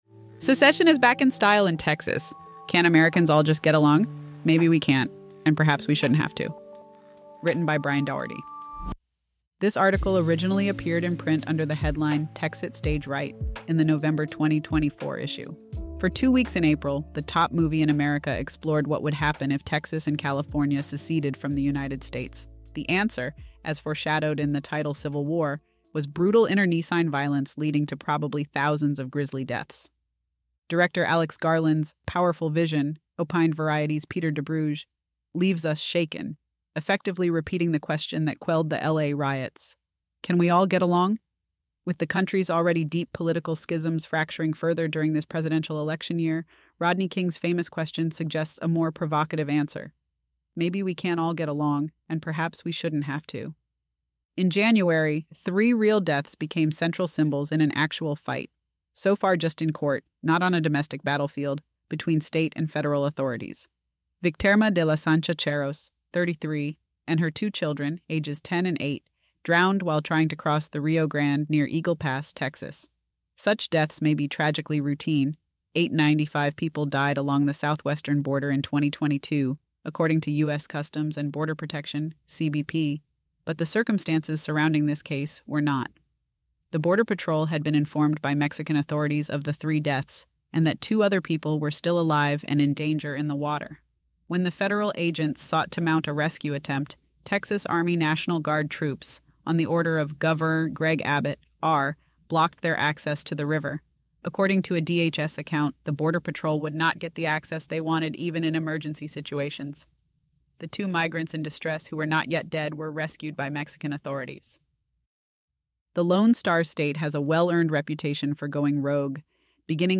This audio was generated using AI